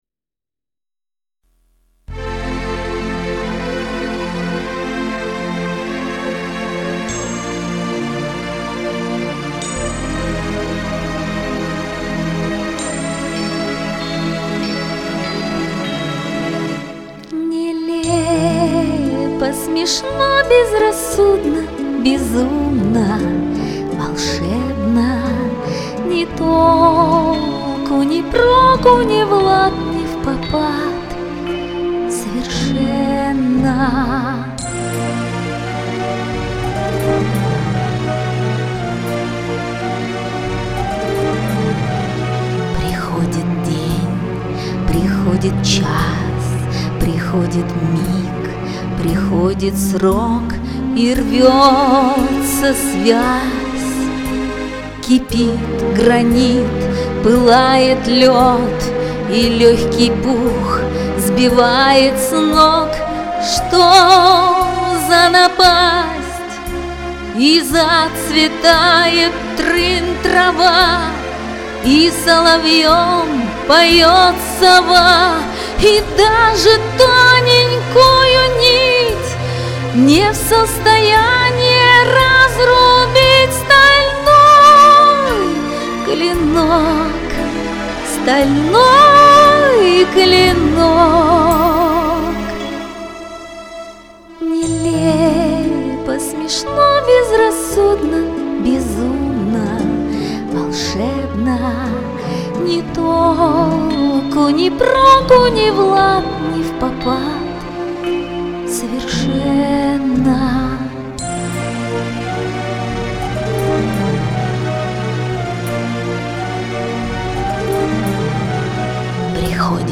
ЗВУЧАНИЕ МИНУСА БОГАЧЕ